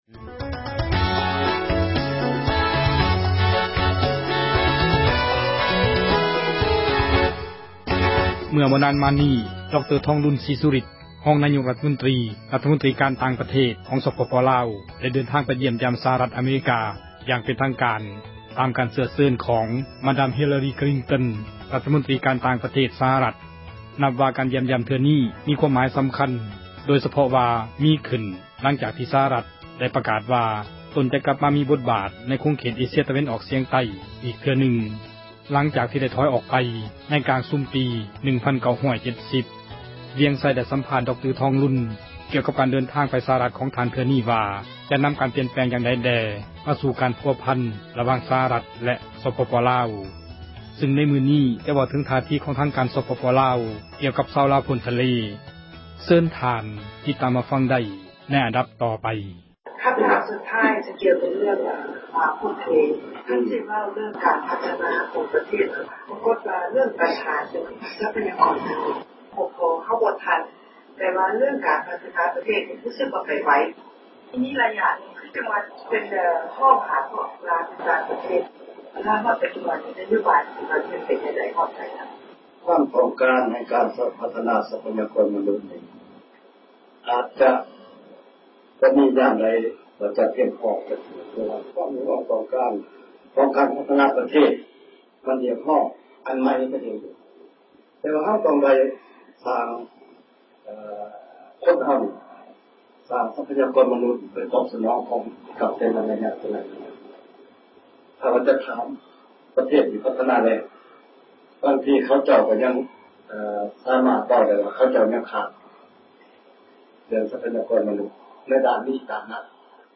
ໄດ້ສັມພາດ ດຣ. ທອງລຸນ ກ່ຽວກັບການ ເດີນທາງໄປ ສະຫະຣັຖ ຂອງ ທ່ານເທື່ອນີ້